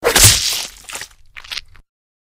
Звуки ниндзя
Звук А теперь клинок вонзается в плоть